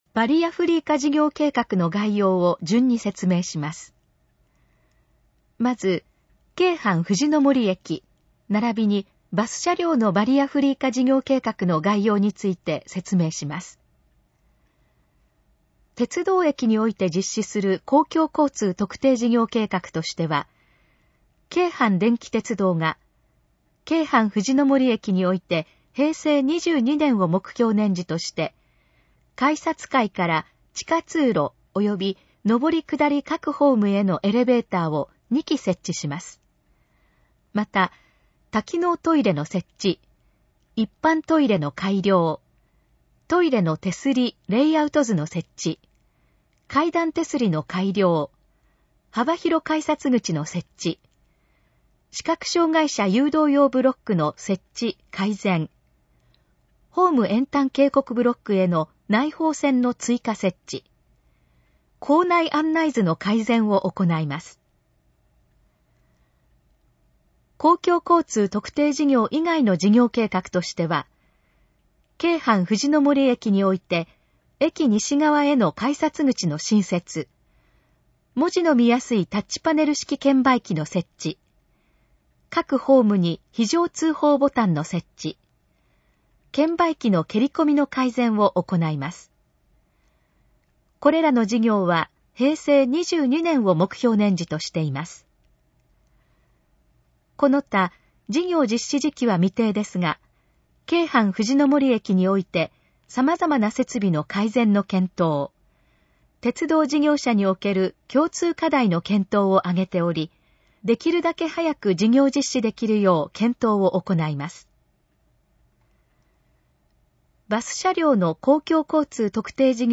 以下の項目の要約を音声で読み上げます。